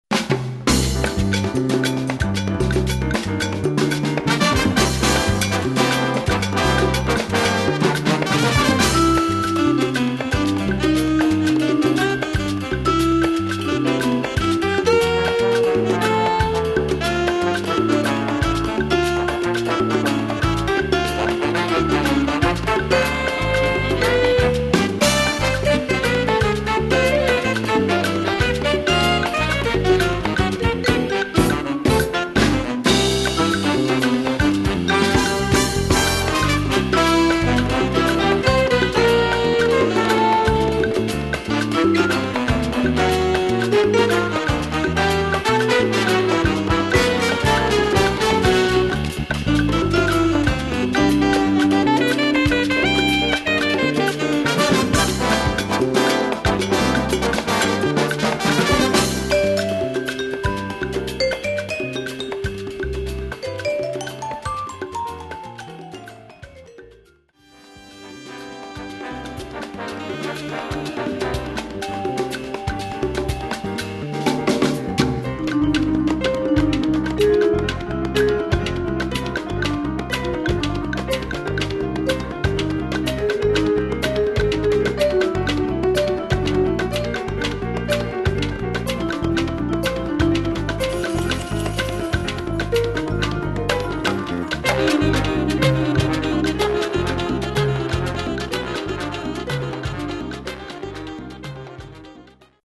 Category: little big band
Style: mambo
Solos: open
Featured Instrument: alto, vibes